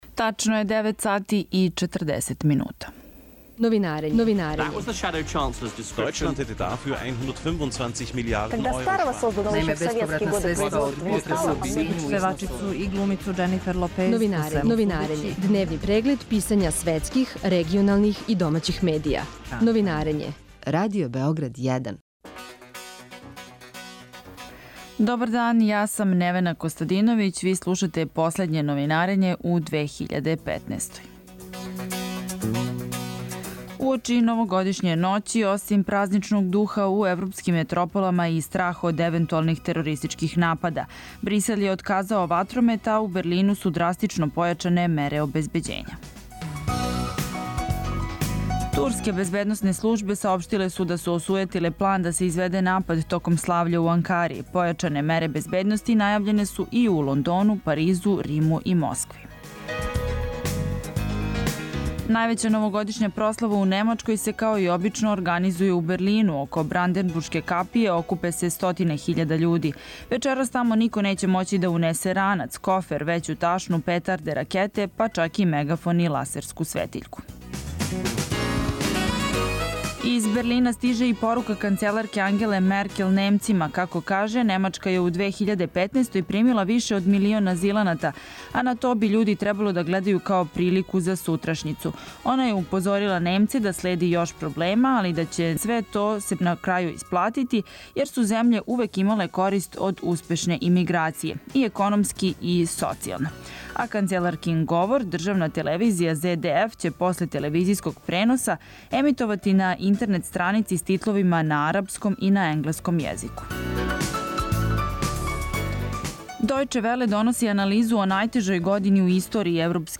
Да ли смо и ми део планова великих сила, за Радио Београд 1 објашњава шеф српске дипломатије Ивица Дачић.